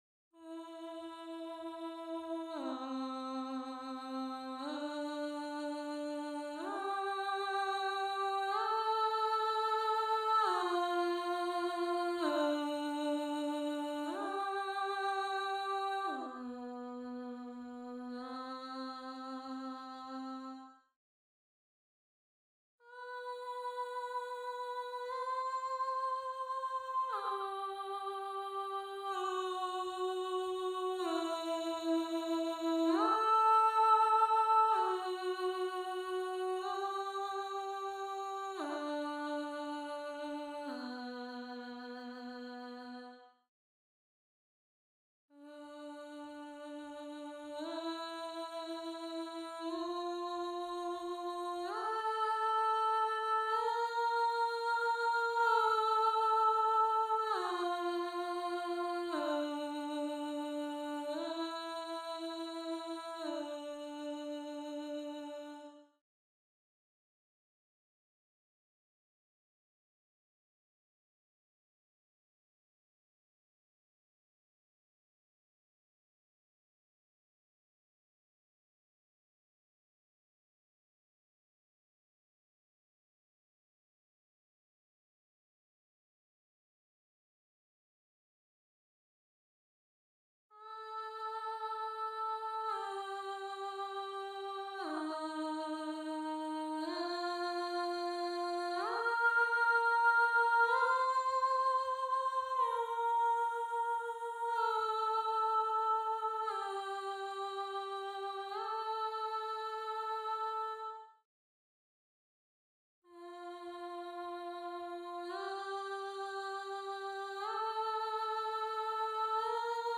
2. ALTO (Alto/Alto)
gallon-v3s1-21-Alto_0.mp3